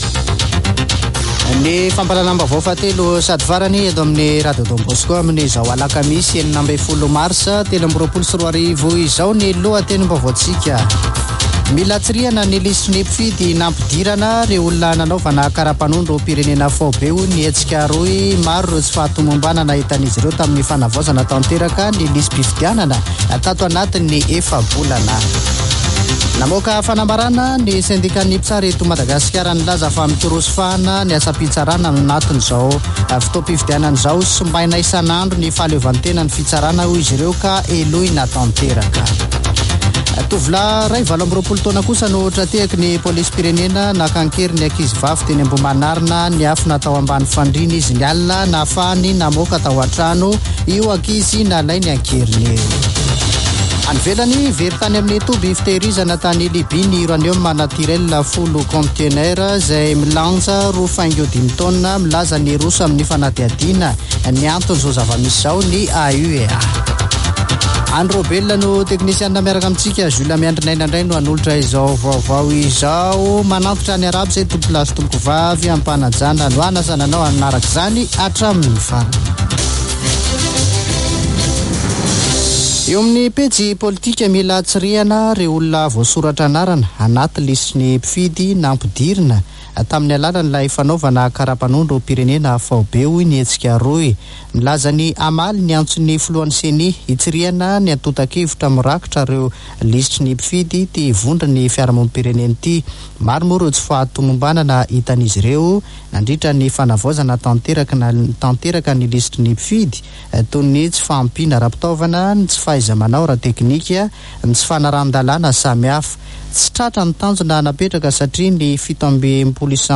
[Vaovao hariva] Alakamisy 16 marsa 2023